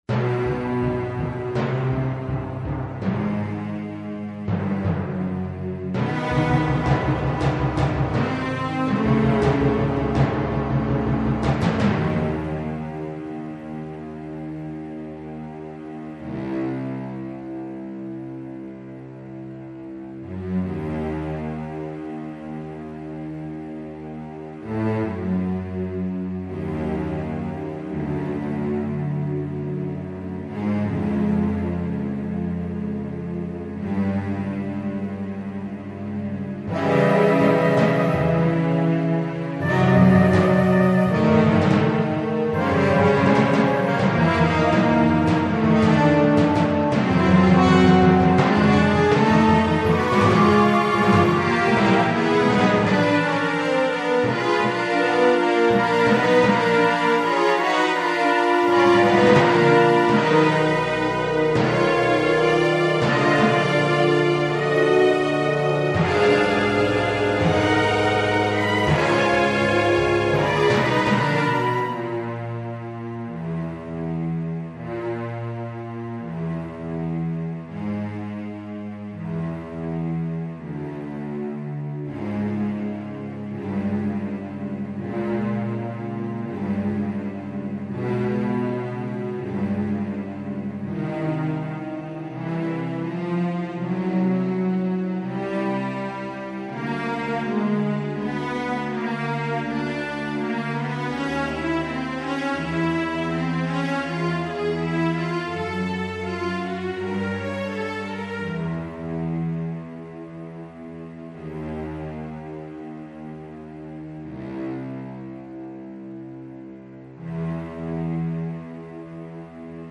描述：古典乐|激越
Tag: 弦乐器 小号